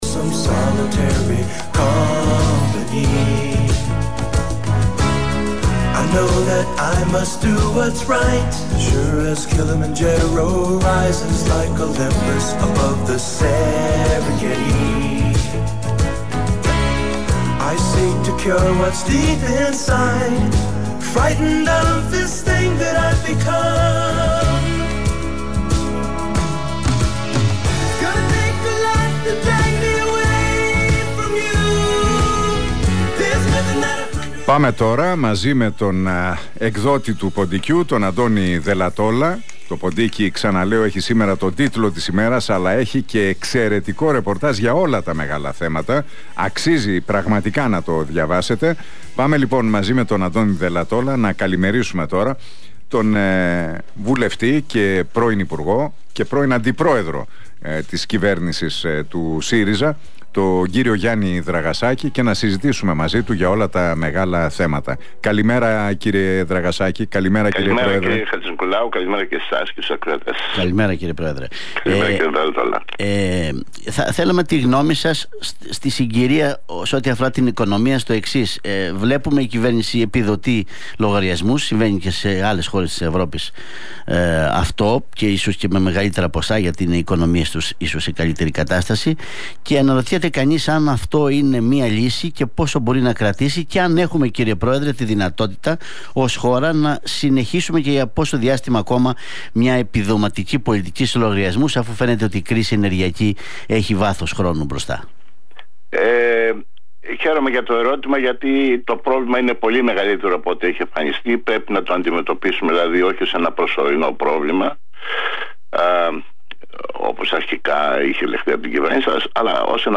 σε συνέντευξή του στον Realfm 97,8